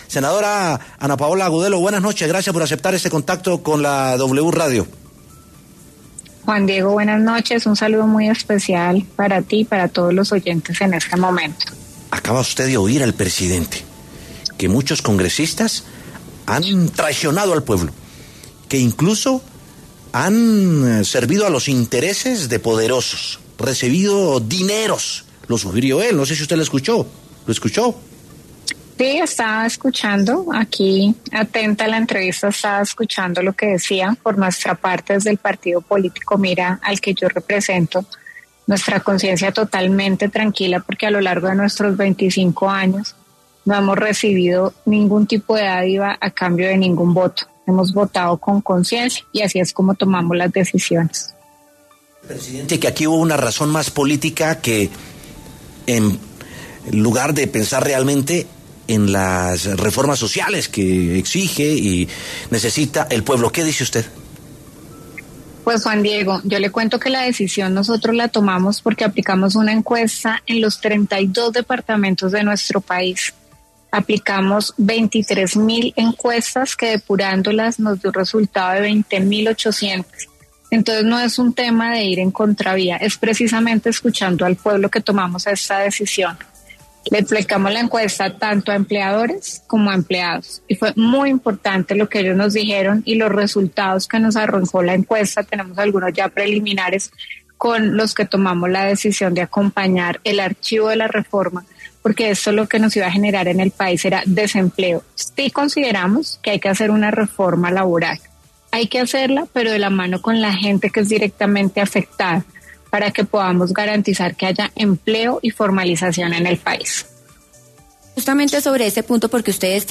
La senadora Ana Paola Agudelo pasó por los micrófonos de W Sin Carreta para hablar sobre el anuncio del presidente Gustavo Petro de recurrir al mecanismo de consulta popular para decidir sobre las reformas laboral y de salud.